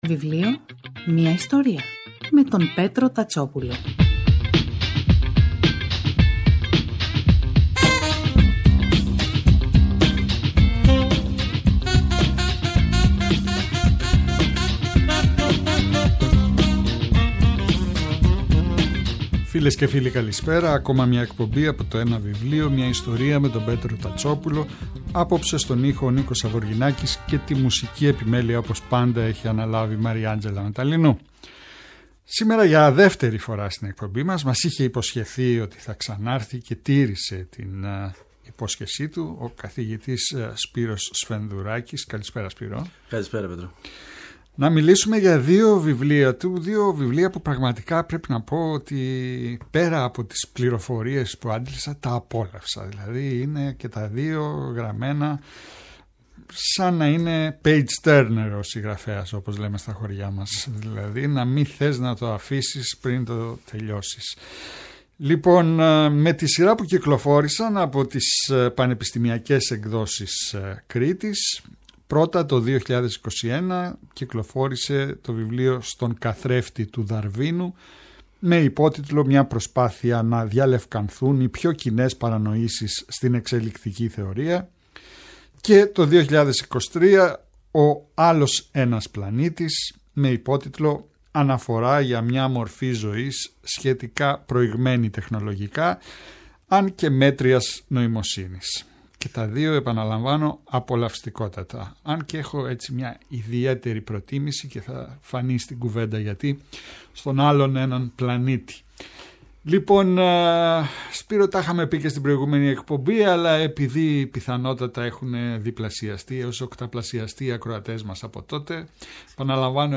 Κάθε Σάββατο και Κυριακή, στις 5 το απόγευμα στο ertnews radio της Ελληνικής Ραδιοφωνίας ο Πέτρος Τατσόπουλος, παρουσιάζει ένα συγγραφικό έργο, με έμφαση στην τρέχουσα εκδοτική παραγωγή, αλλά και παλαιότερες εκδόσεις.